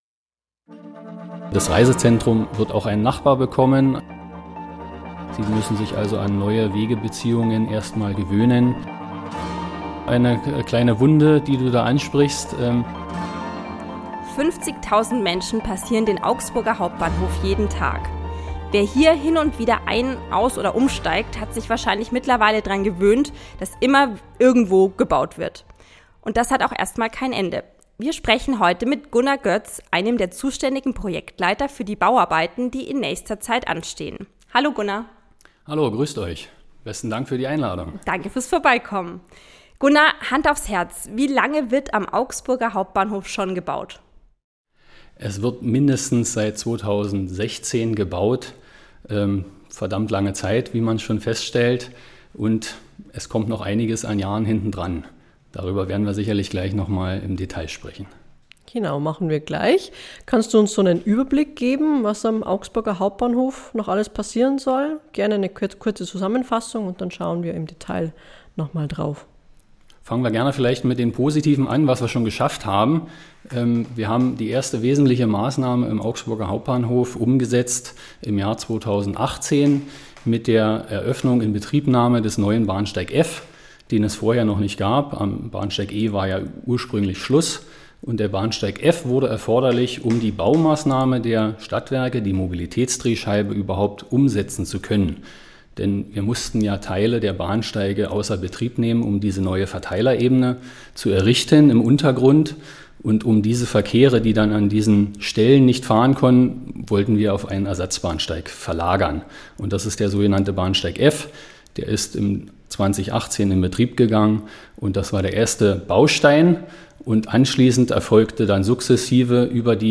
Ein Gespräch über Bauen in Etagen, Denkmalschutz und Brandmeldeanlagen.